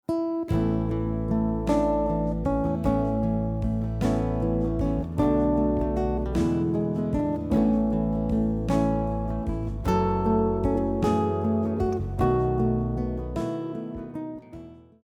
The hymn It is Well with my Soul (a.k.a. When Peace like a River) is a popular Christian hymn written by Horatio Gates Spafford and composed by Phillip Bliss.
In this lesson, we’ll cover the chords, melody with tabs and a finger-style guitar arrangement of this song.
It is Well guitar arrangement
In this section, we are essentially connecting the melody and chords together using a finger picking method of playing.